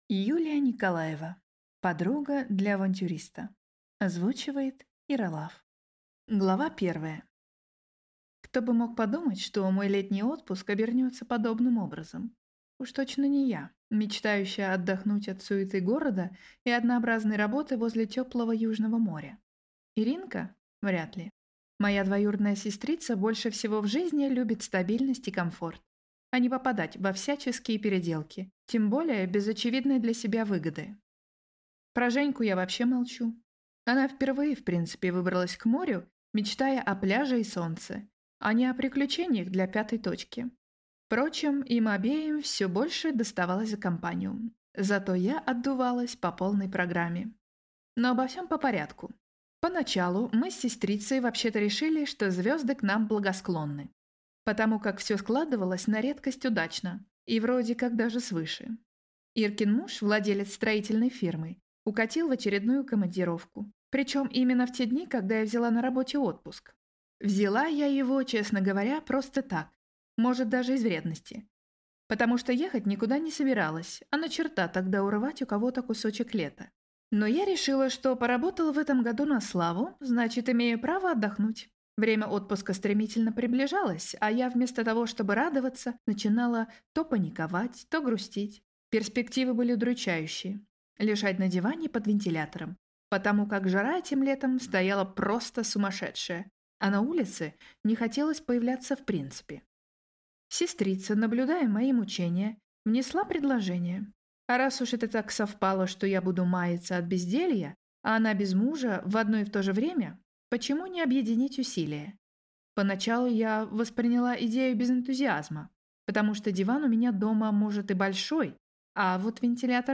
Аудиокнига Подруга для авантюриста | Библиотека аудиокниг
Прослушать и бесплатно скачать фрагмент аудиокниги